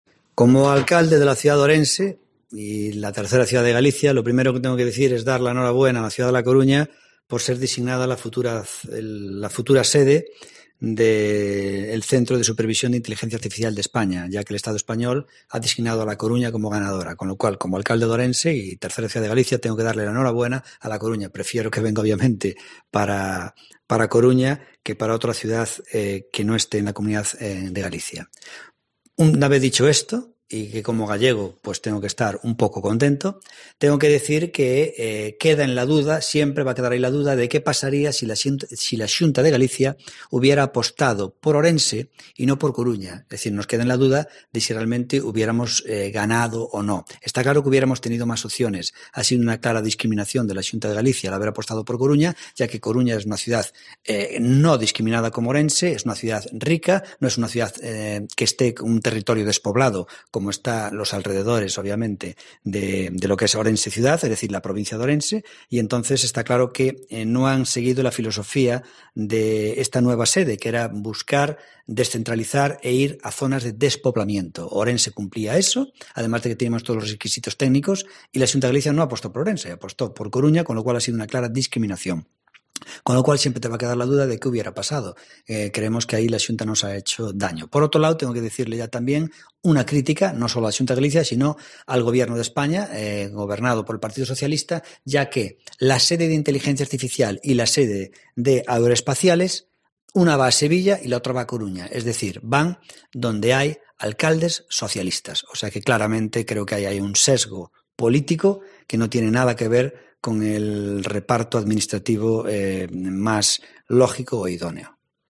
Declaraciones del alcalde de Ourense sobre la elección de A Coruña como sede de la AESIA.